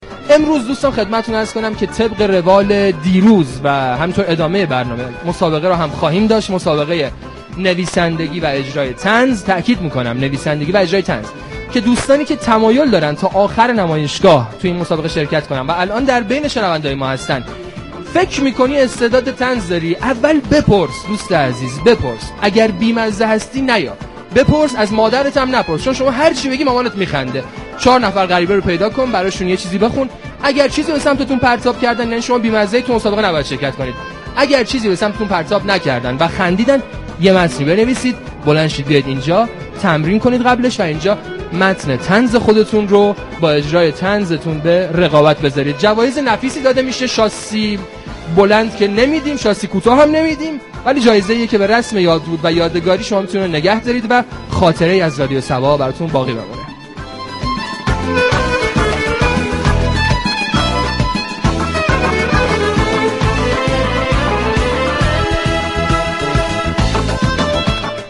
ویژه برنامه «دیباچه» با برگزاری مسابقه زنده بین مراجعان نمایشگاه بین الملی كتاب از محل نمایشگاه بین الملی كتاب تهران راهی آنتن می شود.